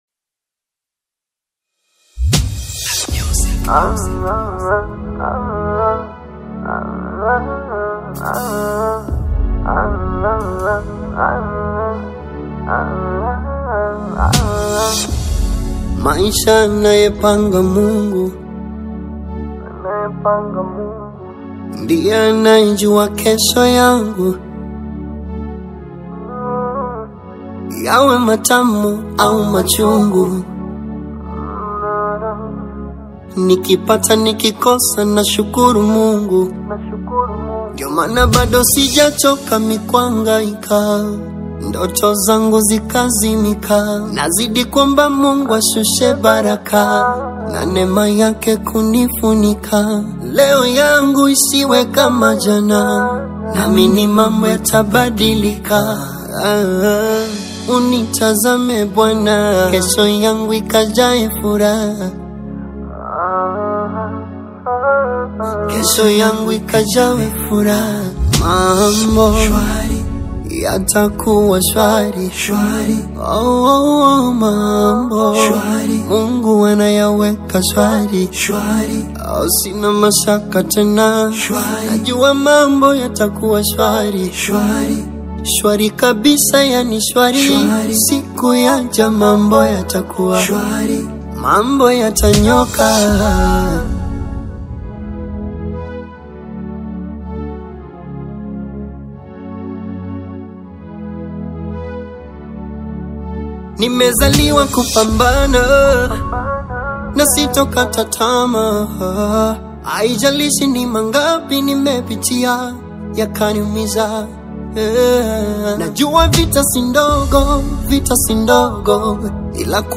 a Tanzanian singer and songwriter.
a soothing and uplifting tune
The song begins with a gentle guitar intro
smooth and soulful vocals
The chorus also adds a background choir
creating a rich and uplifting sound.